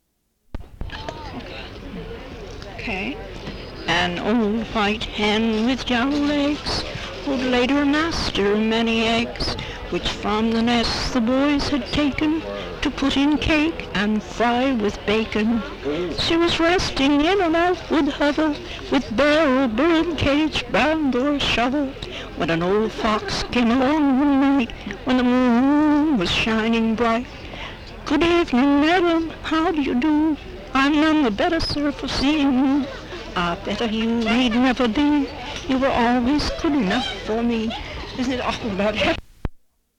Folk songs, English--Vermont (LCSH)
sound tape reel (analog)
Location Fairlee, Vermont